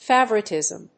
音節fá・vour・it・ìsm 発音記号・読み方
音節fa･vour･it･ism発音記号・読み方féɪv(ə)rətɪ̀z(ə)m